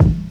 50 cent kick 3.wav